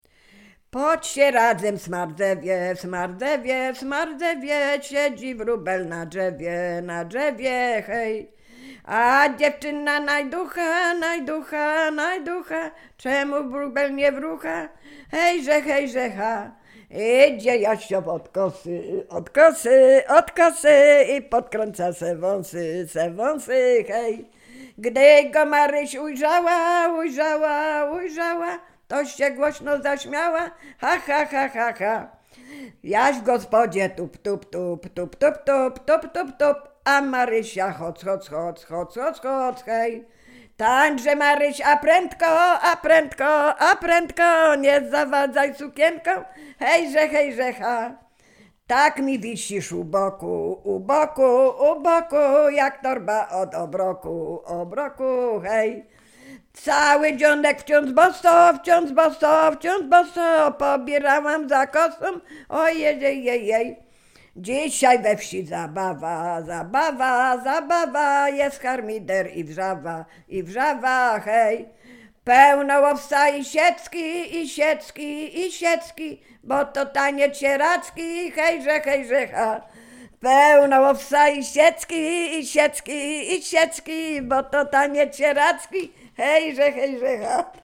wojewódzctwo łódzkie, powiat sieradzki, gmina Brzeźnio, wieś Kliczków Mały
liryczne miłosne pieśni piękne żartobliwe